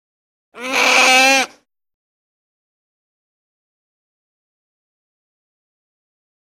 Звуки выдры